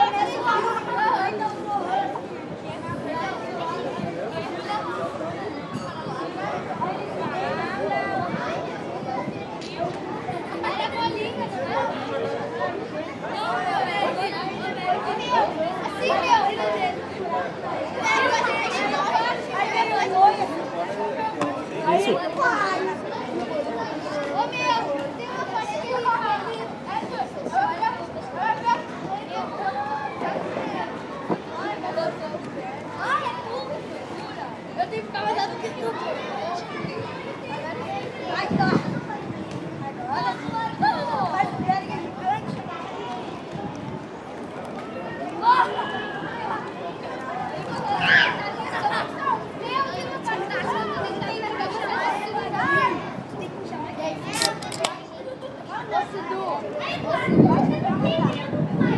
Algazarra de crianças e adolescentes
No pátio do bar da Arquitetura, UFRGS